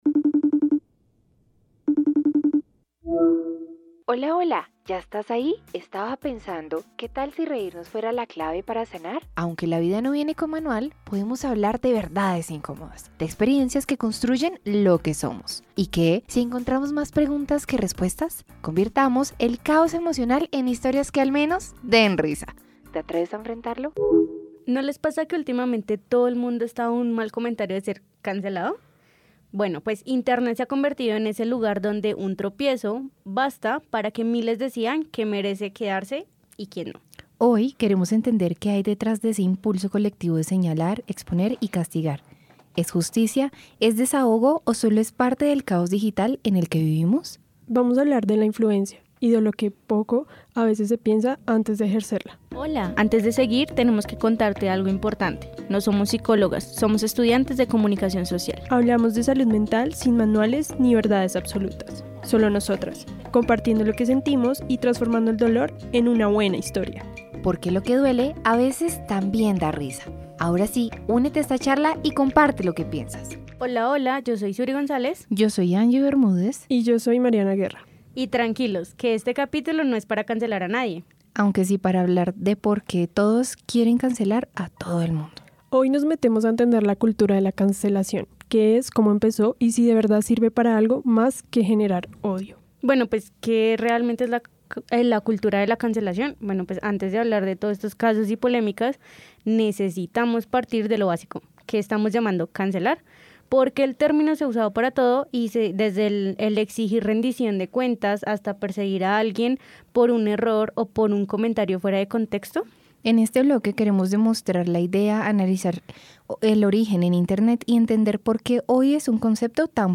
Él Árbol Rojo: conversación con la lectora y escritora en crecimiento